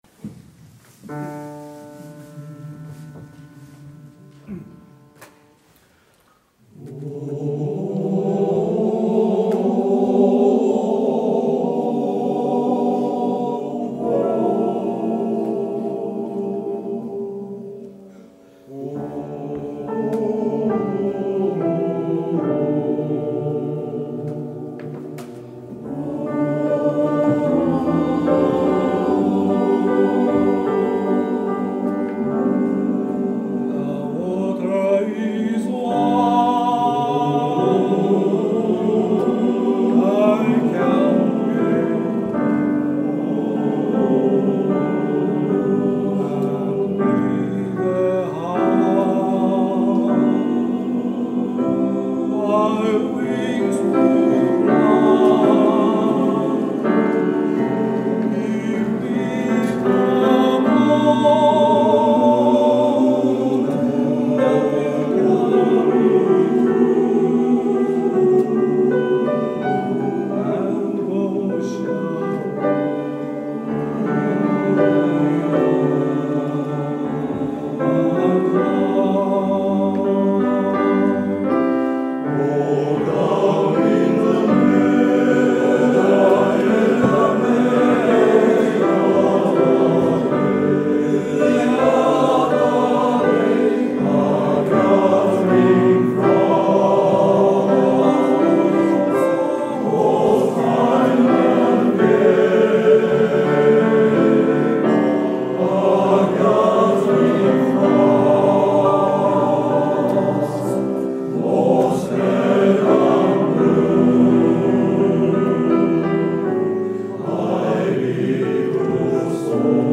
ダンデイーズ第9回演奏会
ピアノ